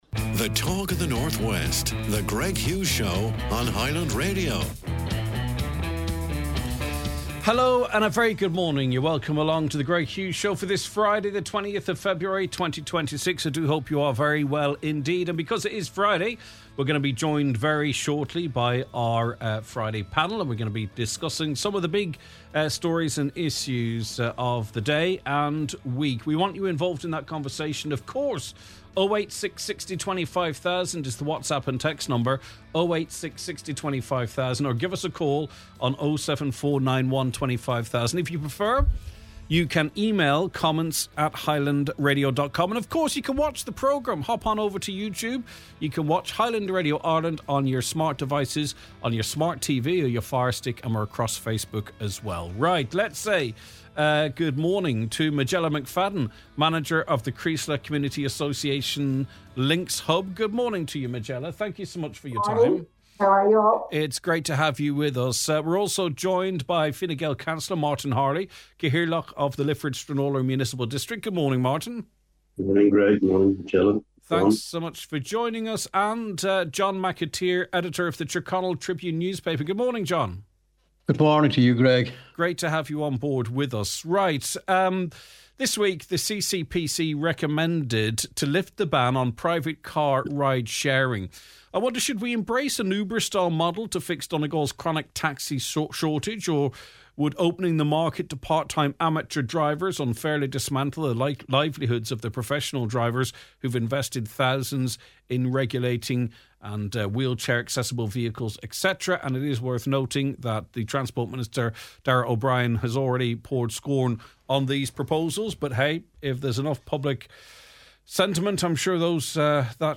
It’s the Friday Panel, and the sparks are already flying.